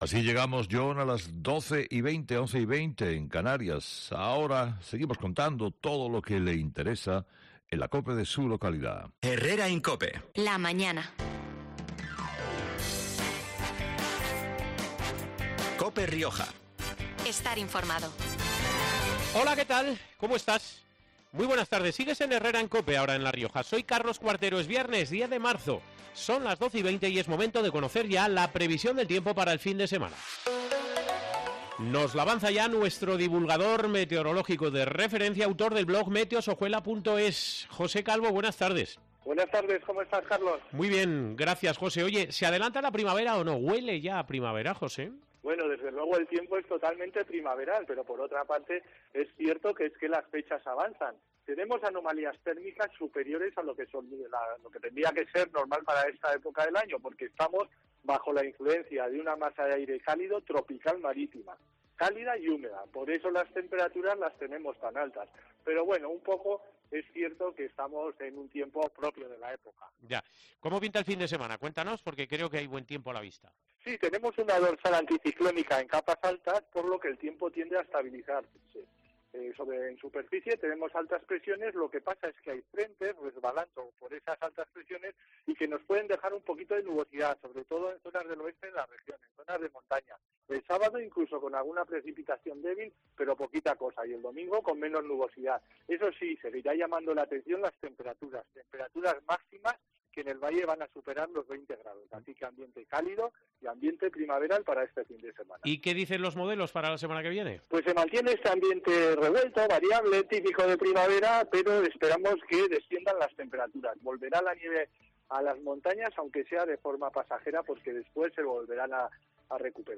El diestro riojano ha concedido una entrevista telefónica este viernes a COPE Rioja